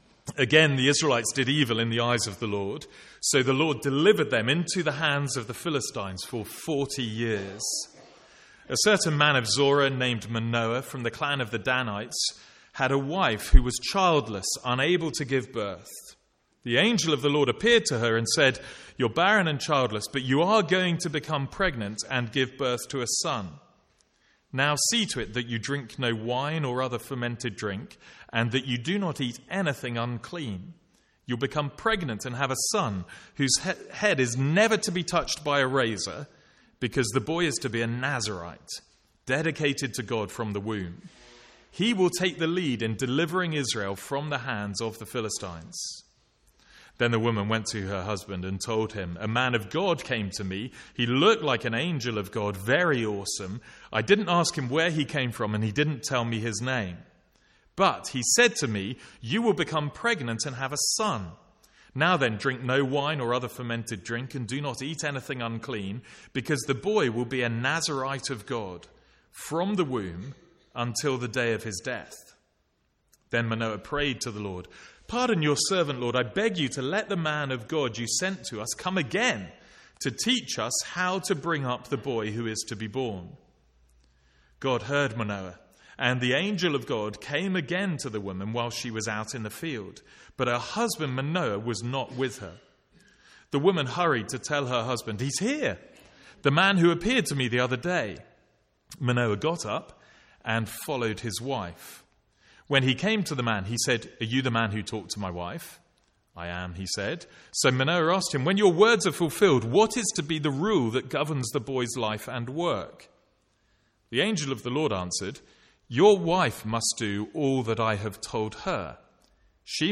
From the Sunday morning series in Judges.